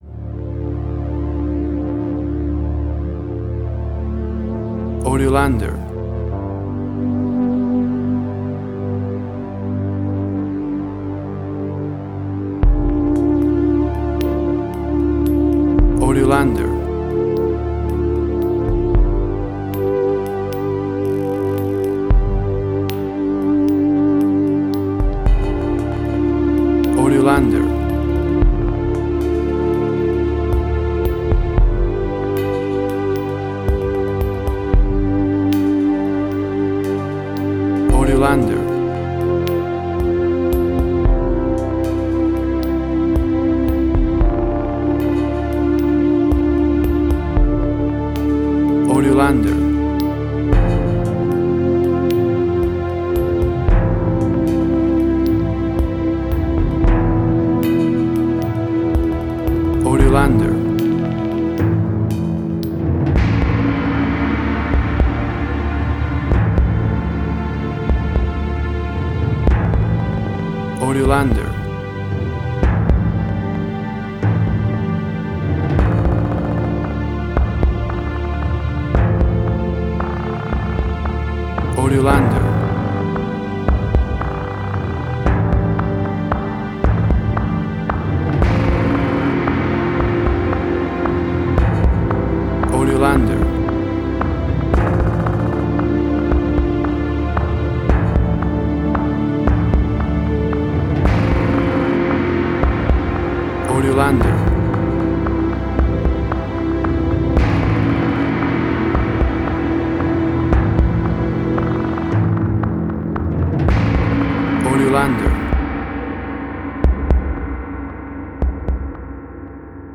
Suspense, Drama, Quirky, Emotional.
Tempo (BPM): 113